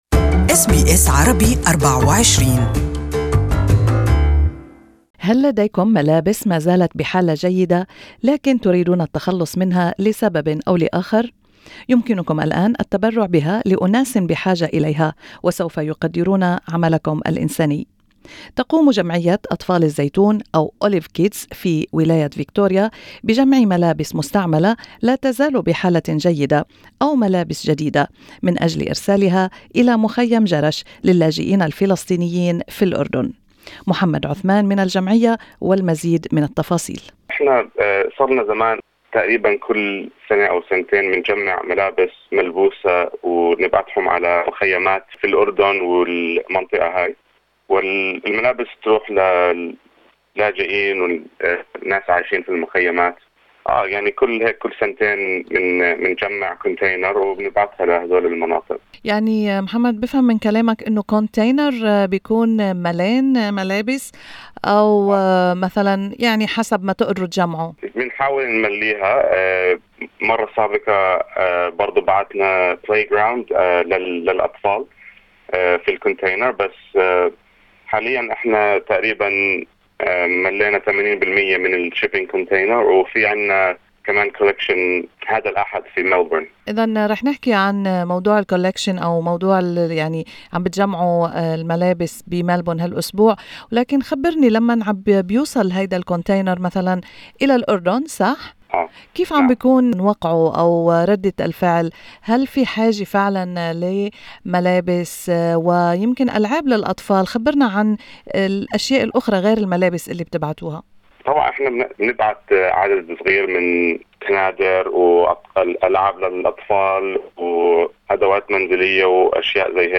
An interview in Arabic